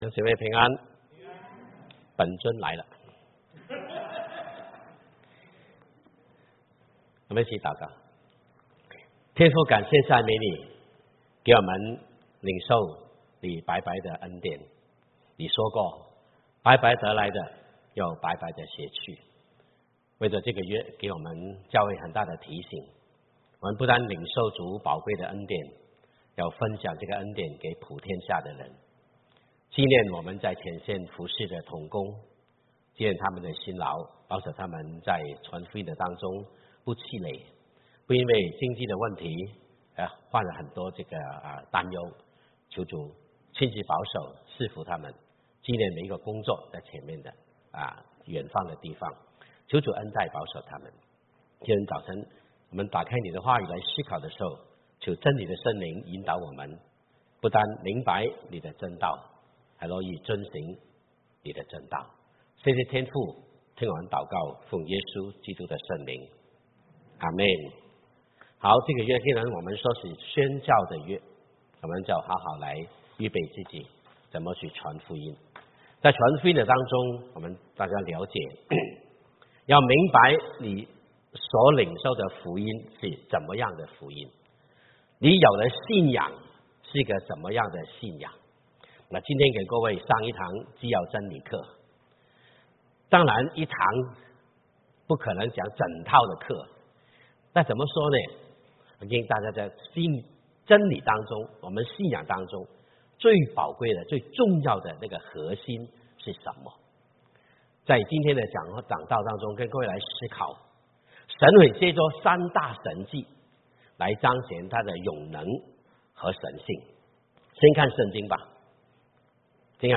The Home of Christ Church in Cupertino